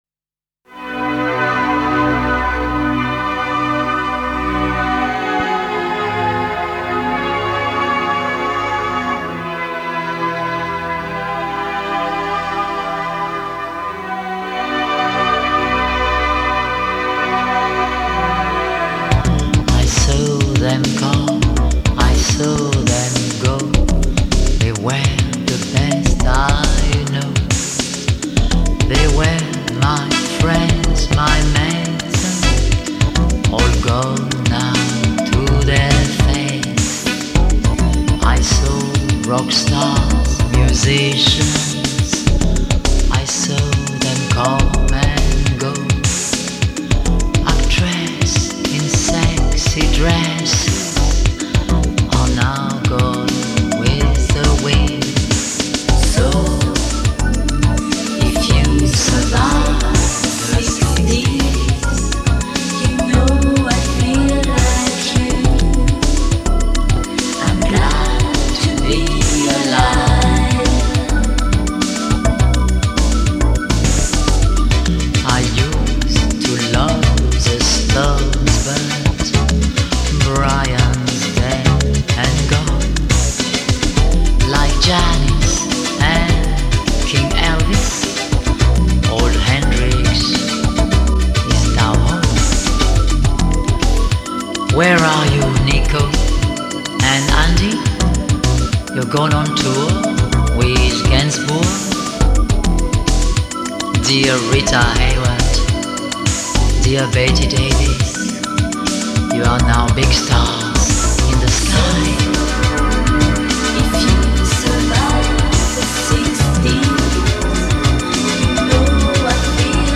меланхоличная автобиографическая баллада